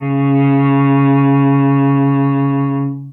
interactive-fretboard / samples / cello / Cs3.wav
Cs3.wav